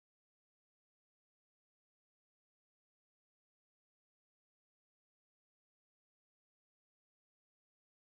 8sek_stille.mp3